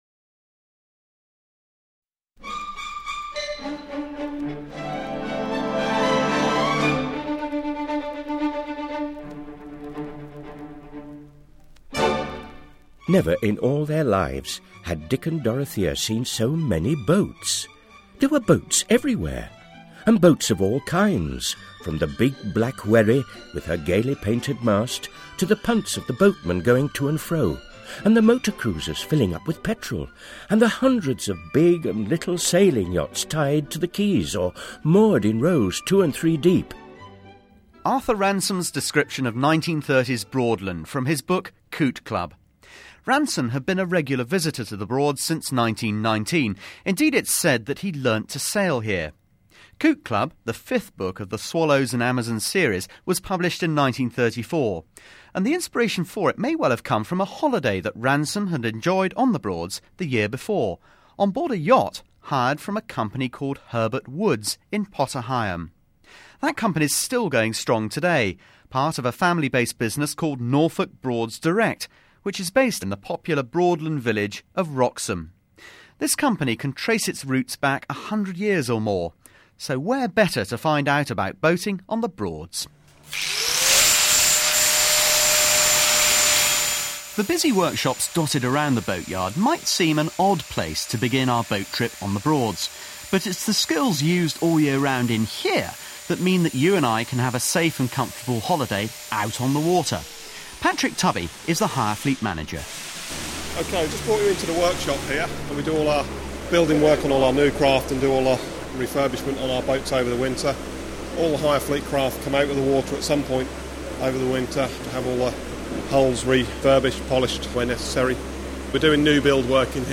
This set of podcasts was funded from the Broads Authority’s Sustainable Development Fund and produced by the local digital media company Aperçu.